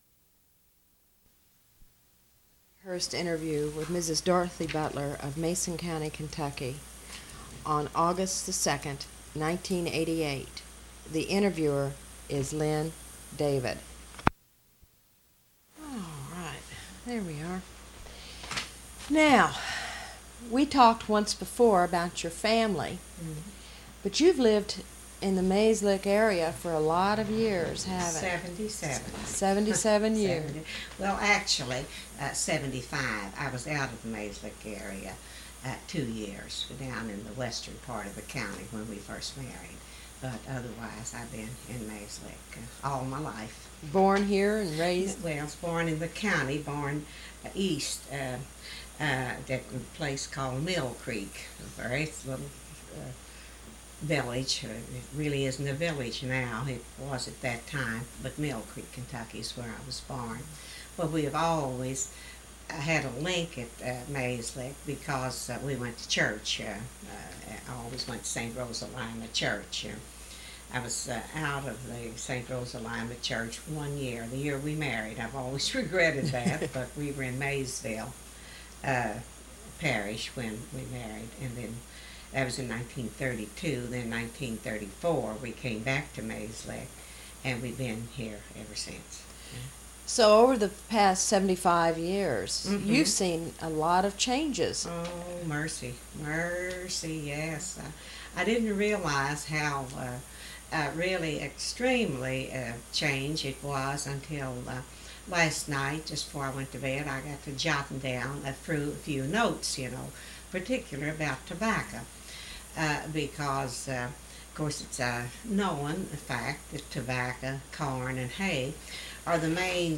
Interview Summary